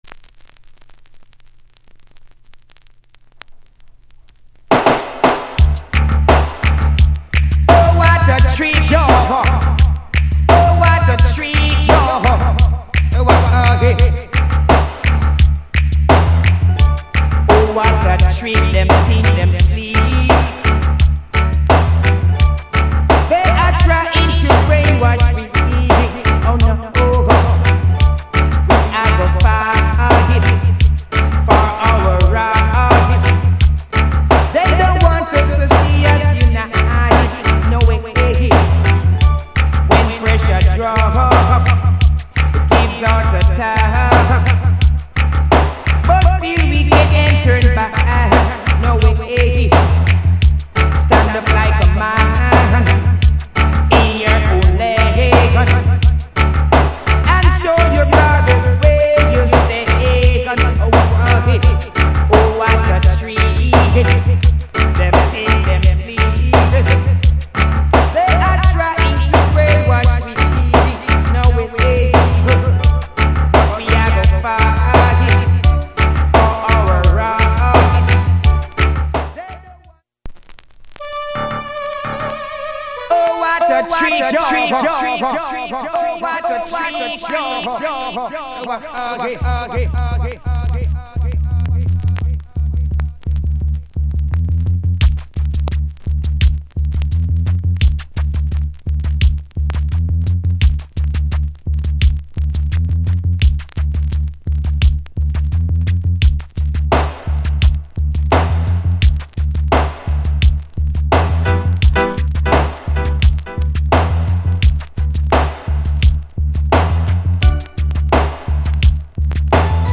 '87 Heavy Digital Riddim Good Vocal